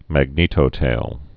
(măg-nētō-tāl)